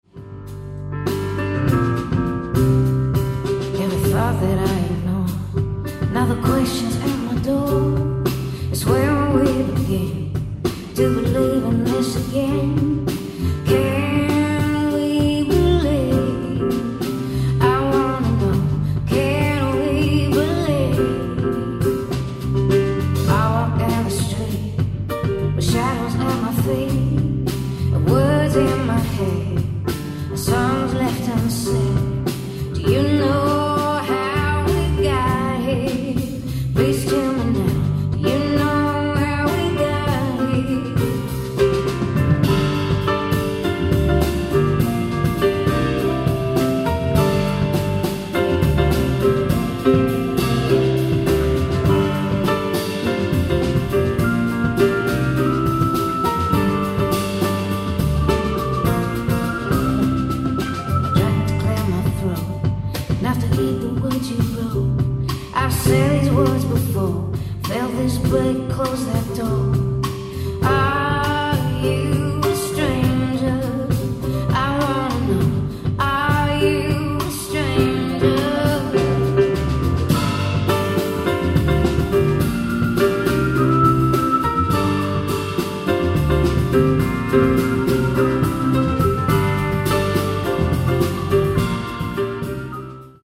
ライブ・アット・パレス・シアター、メルボルン、オーストラリア 04/12/2019